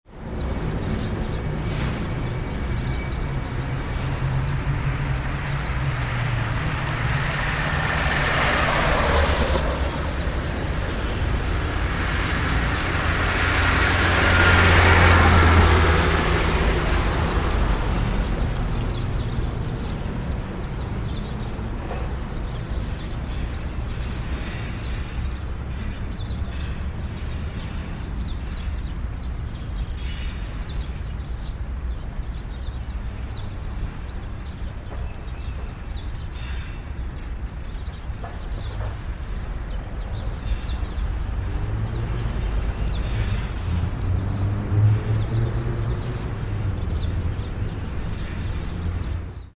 AMBIENTE DE CALLE
Ambient sound effects
Ambiente_de_calle.mp3